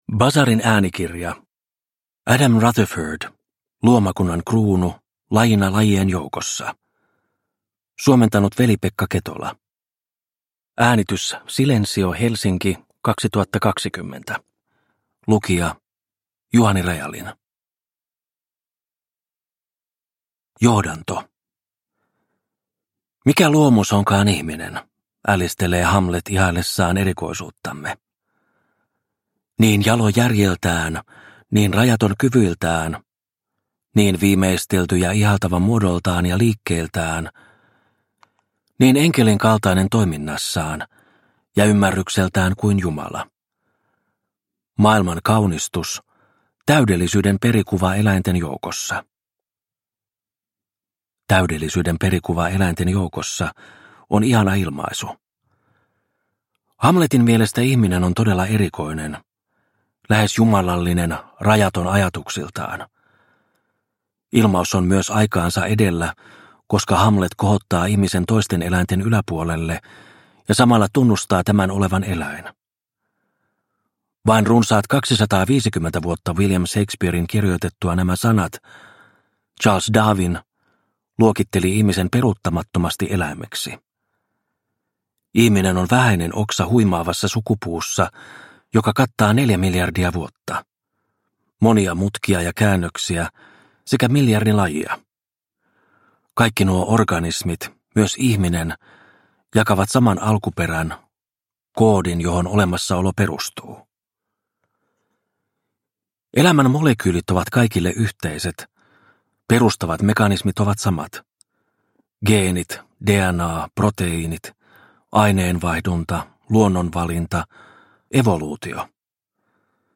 Luomakunnan kruunu – Ljudbok – Laddas ner